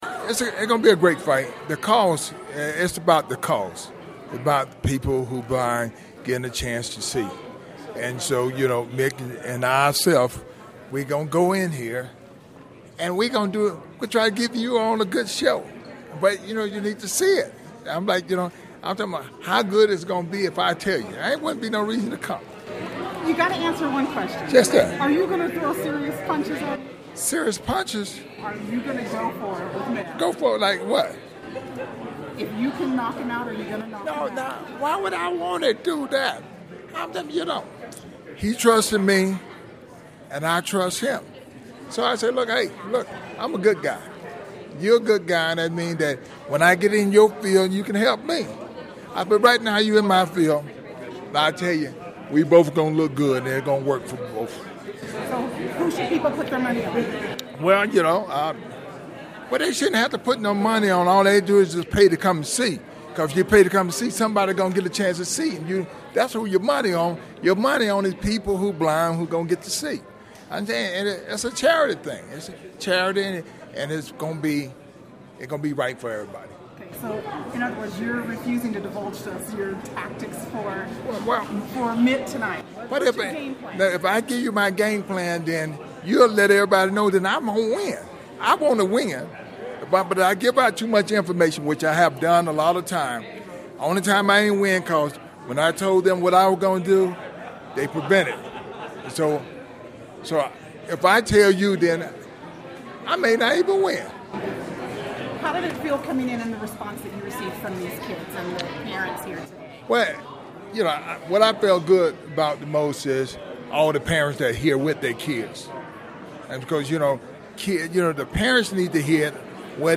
talked with boxing champ Evander Holyfield this morning at an event in South Salt Lake. She asked him about tonight's fight with Governor Mitt Romney.